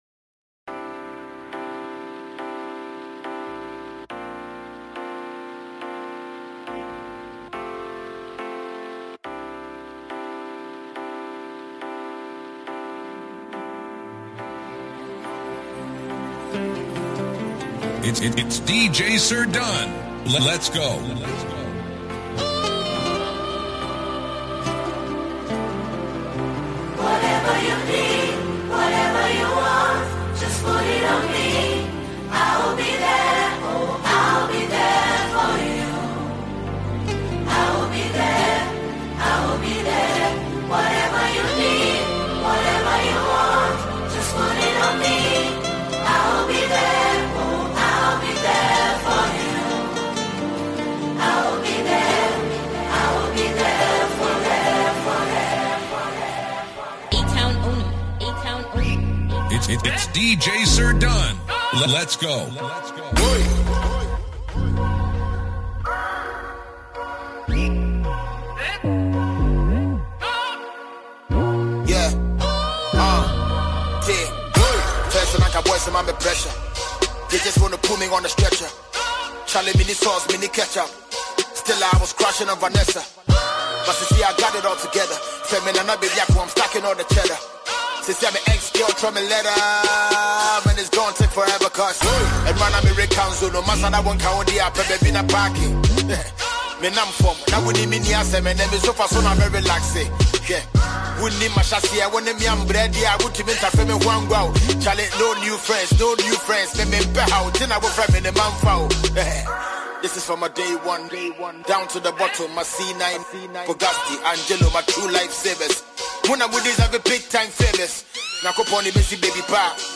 a celebrated Ghanaian disc jockey
Ghanaian rap music
Genre: Mixtape